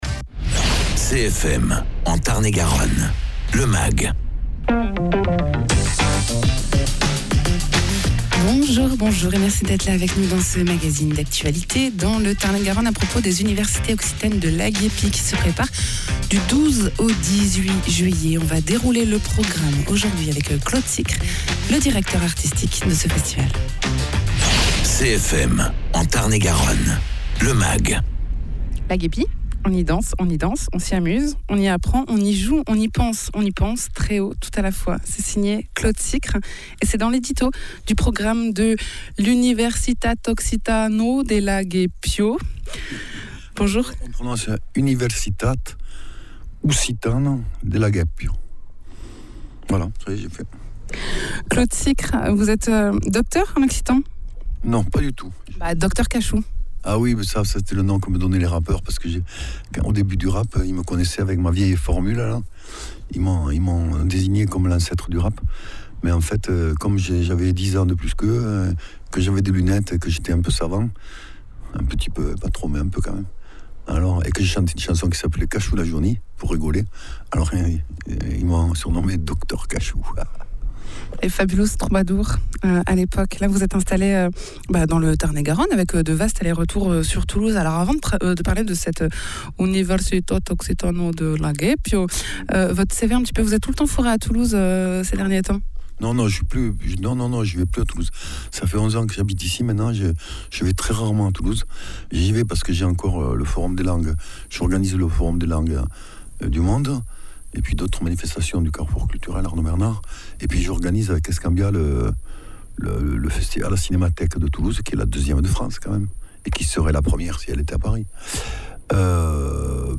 Invité(s) : Claude Sicre, directeur artistique